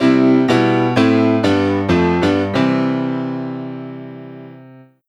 Track 13 - Piano 01.wav